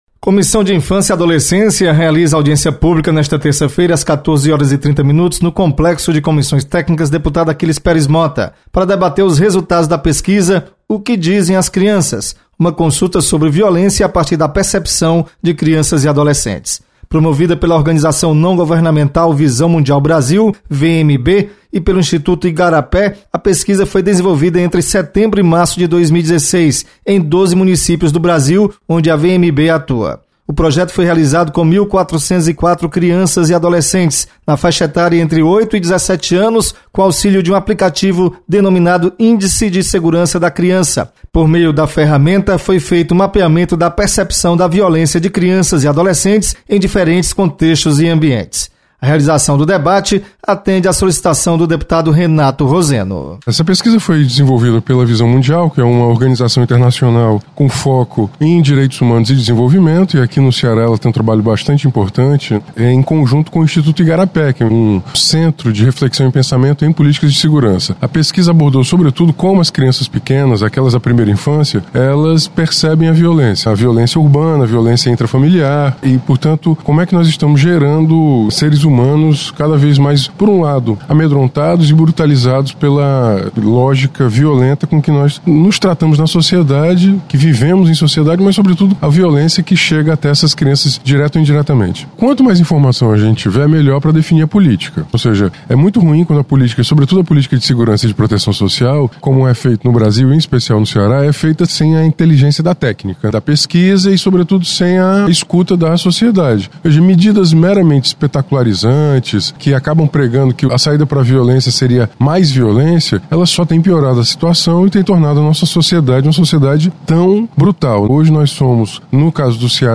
Comissão de Infância e Adolescência debate sobre violência contra crianças e adolescentes. Repórter